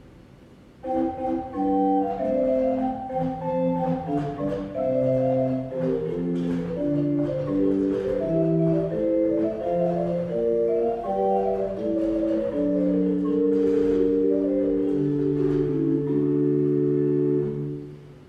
Gedeckt 8'
Das Instrument befindet sich in gutem Zustand, ist recht mild intoniert und daher für kleine Kirchenräume oder als Haus- und Übeorgel gut verwendbar.